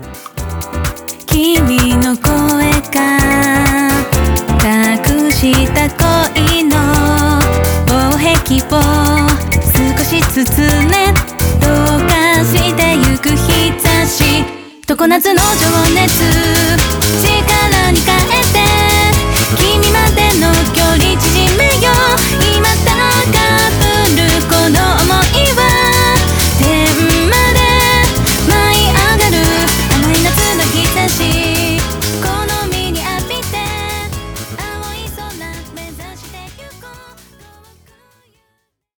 クロスフェードmp3
JPOP
HOUSE
バラード
TRANCE